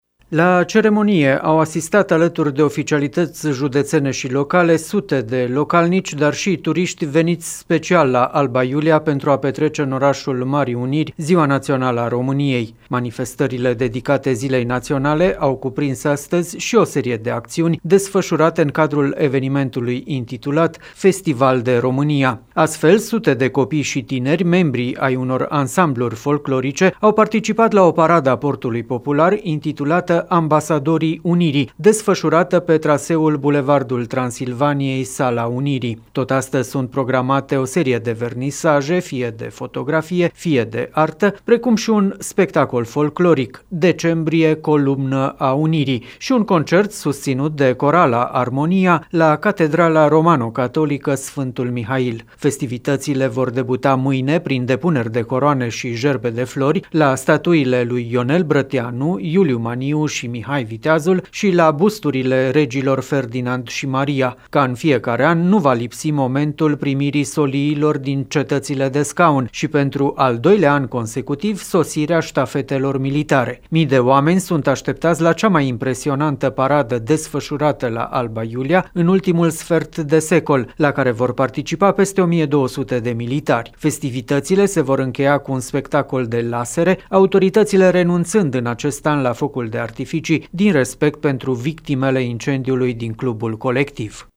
trimisul nostru special